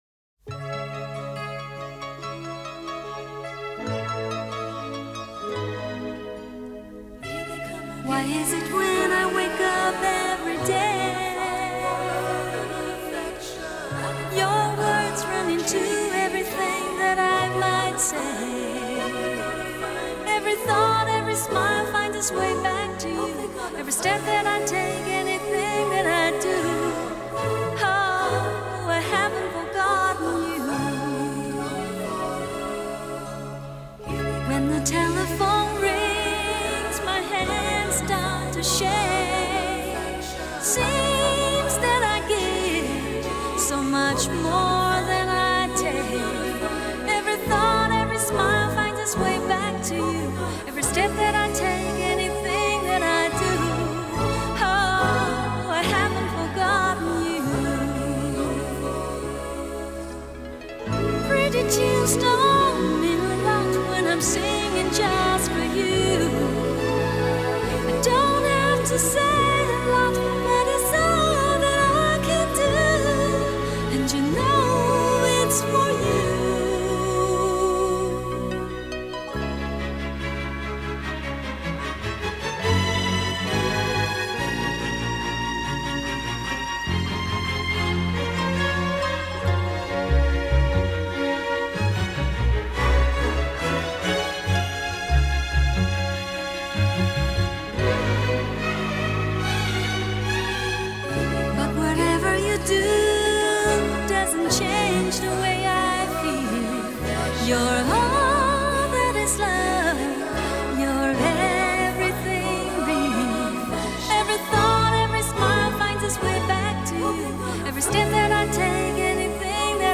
☆专业24bit混音母带处理，原音高品质重现
优雅的英文咬字，伴随张力十足的情感拿捏与澎湃感人的唱功，总是让人无法自拔地如痴如醉。
音樂類型：華語流行音樂